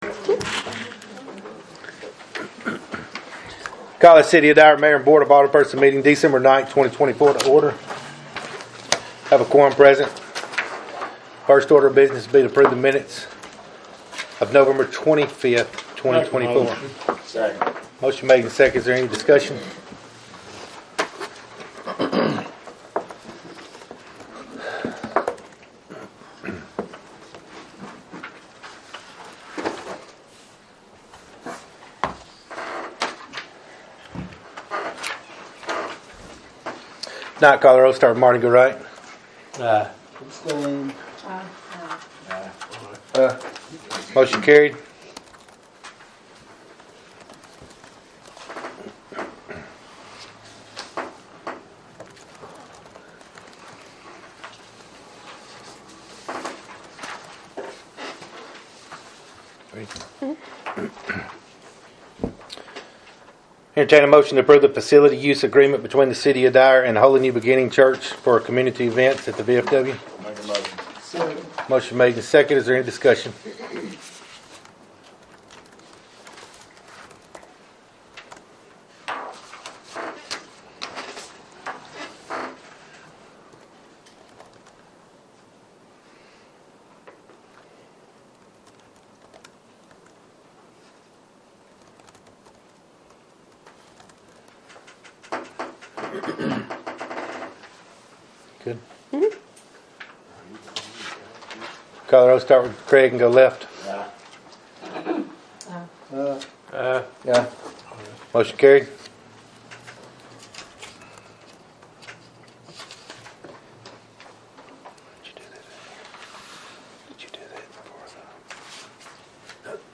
Audio, agenda, and minutes from the December 9, 2024 regular meeting.